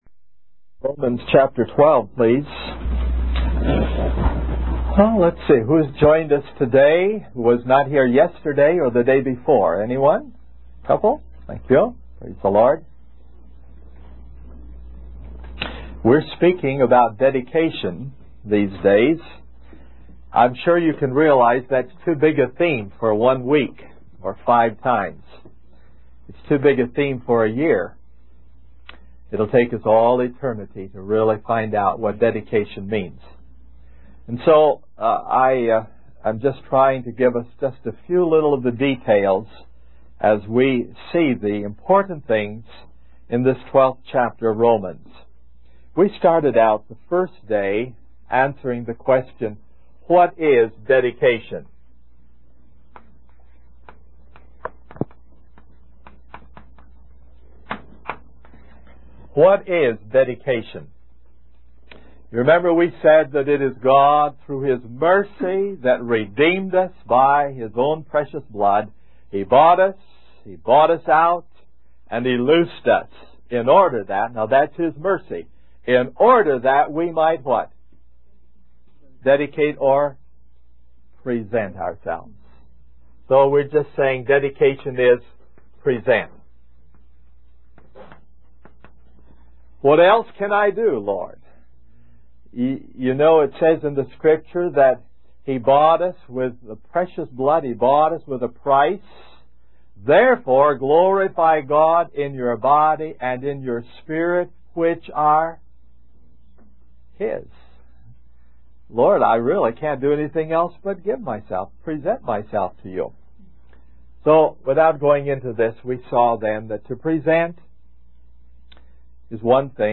In this sermon, the speaker emphasizes the importance of moving beyond the foundational teachings of the faith and progressing in our spiritual journey. He shares a story of a young man who was curious about the joy and love he saw in a group of believers and eventually came to know the Lord. The speaker highlights the need for a heart transformation and wholehearted devotion to God.